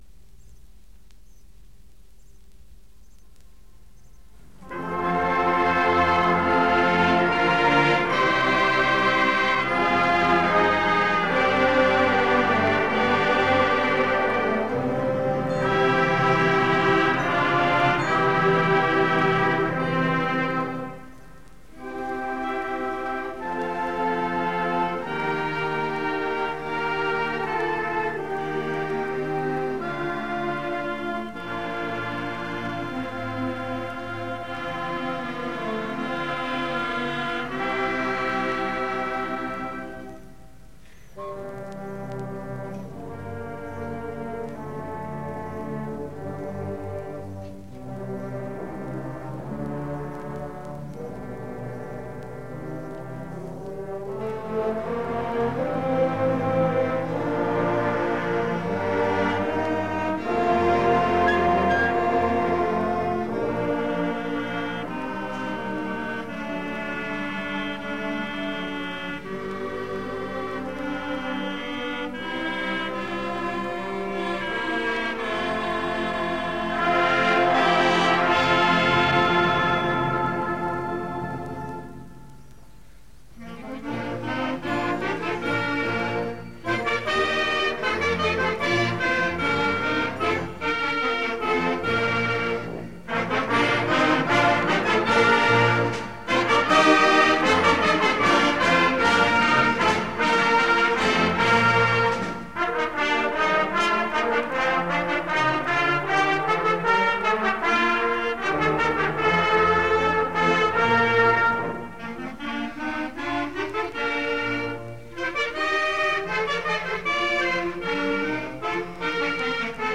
Advanced Band